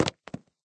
break_drop.ogg